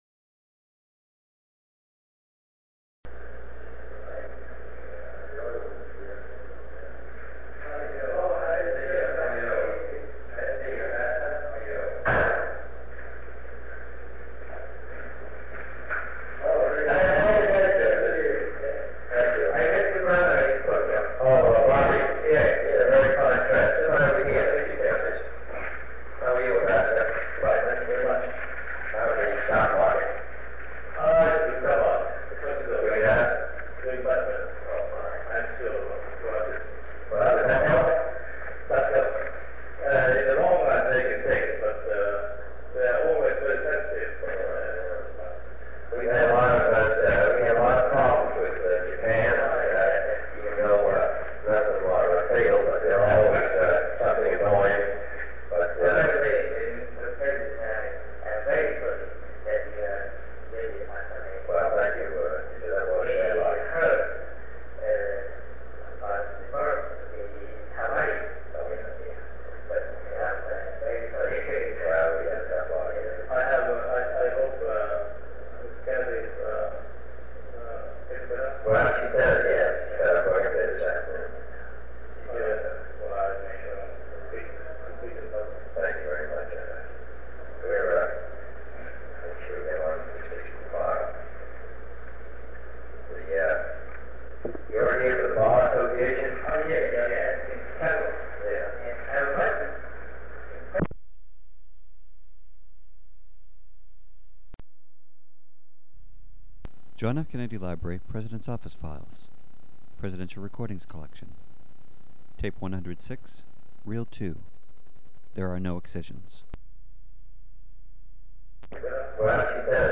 Sound recording of a meeting held on August 16, 1963, between President John F. Kennedy and visitors from Japan, Kisaburo Yokota and Ryuji Takauchi. They discuss the Japanese judicial system.